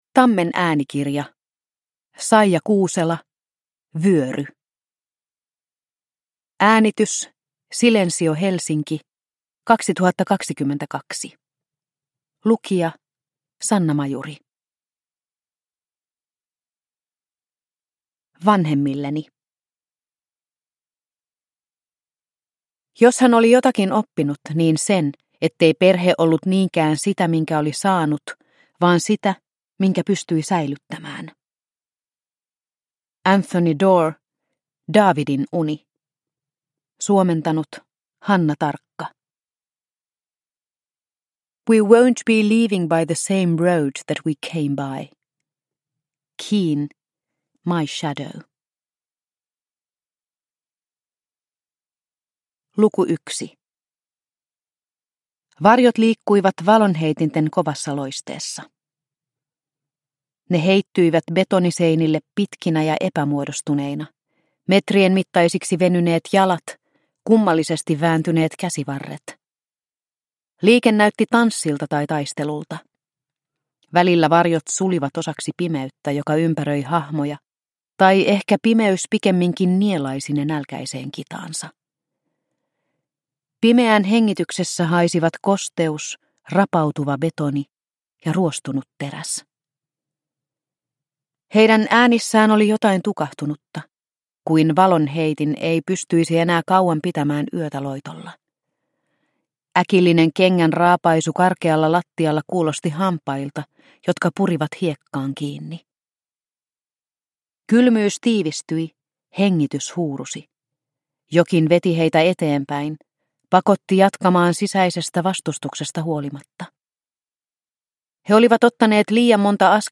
Vyöry (ljudbok) av Saija Kuusela | Bokon